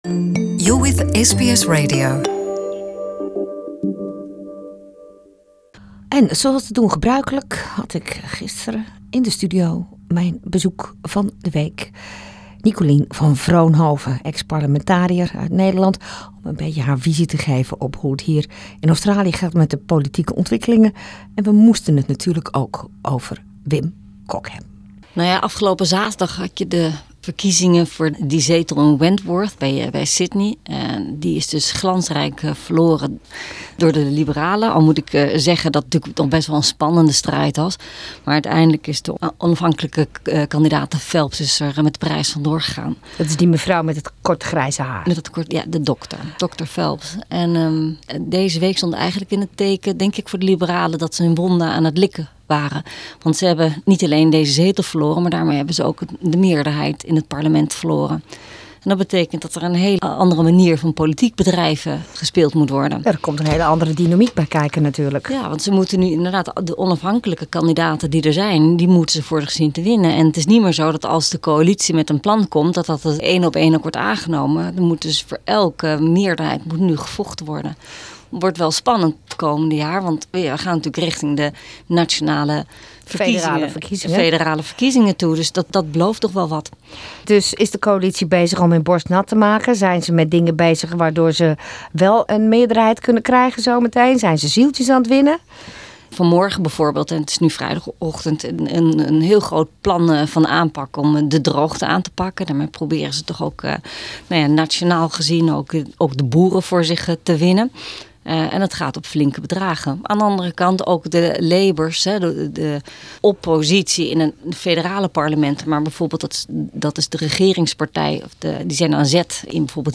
Special reporter for Political Affairs, Dutch ex-parliamentarian Nicolien van Vroonhoven on the post Wentworth wound licking by the liberal party and about the simple tastes of the late ex-prime minister Wim Kok: buttermilk and a cheese roll.